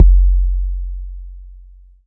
kits/RZA/Kicks/WTC_kYk (9).wav at main